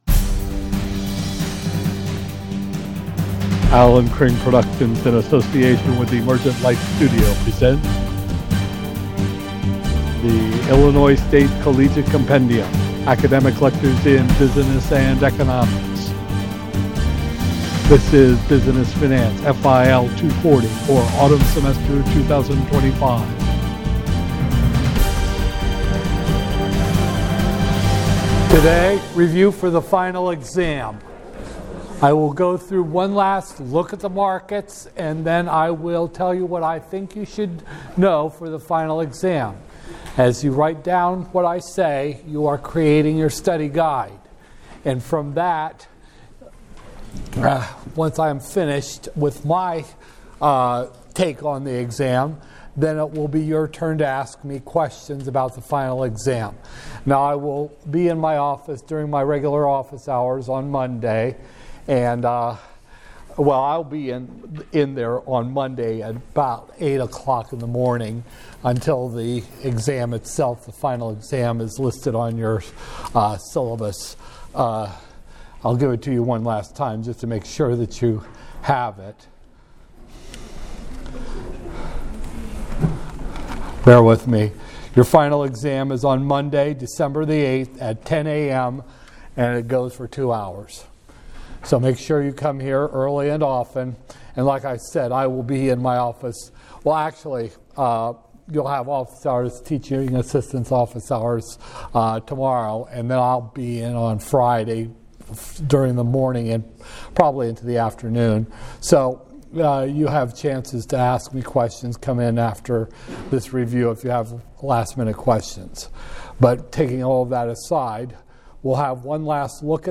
Illinois State Collegiate Compendium - Business Finance, FIL 240-001, Spring 2025, Lecture 29